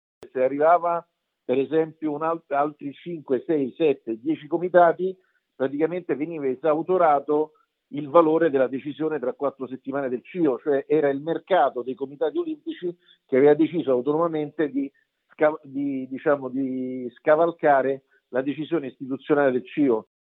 L'intervista di LumsaNews